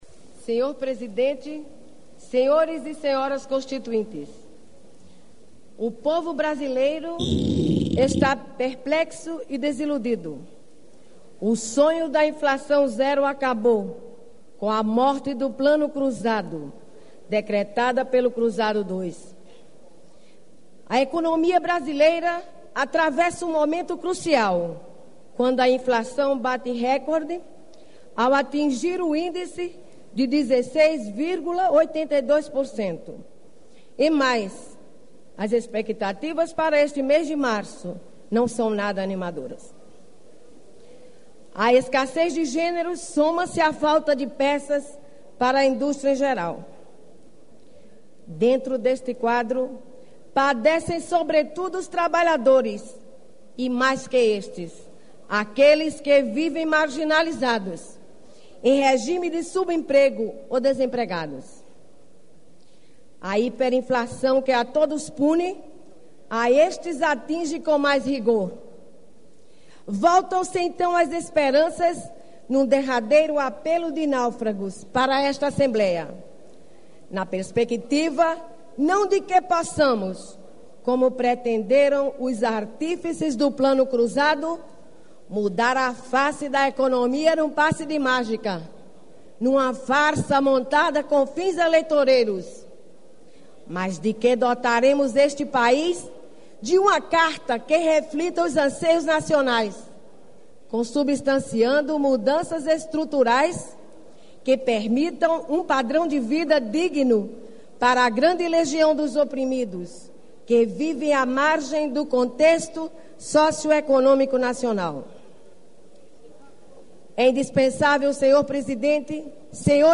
- Discurso pronunciado em 19 de fevereiro de 1987 – Princípios norteadores da elaboração constitucionalApresenta os princípios que devem nortear a elaboração da nova carta constitucional, que deverá ensejar a superação das desigualdades sociais e a promoção do progresso e do bem-estar.